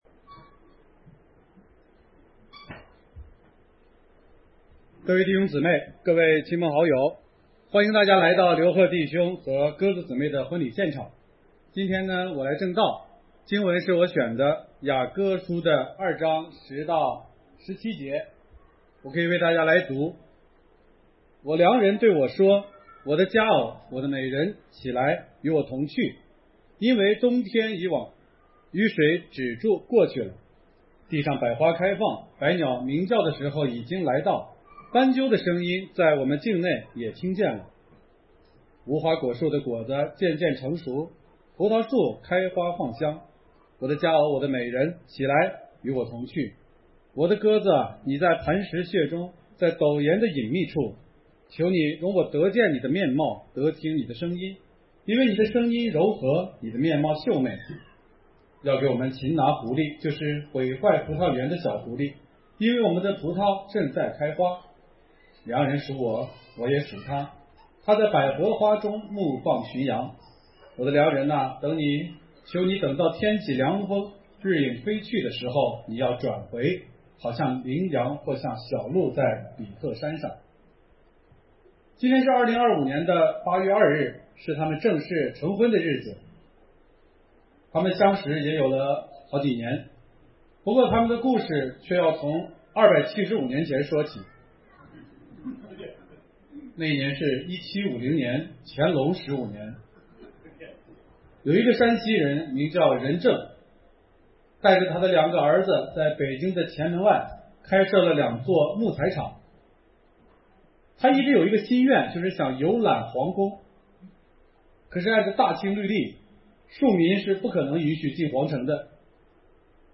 一场事先张扬的婚礼（压缩）.mp3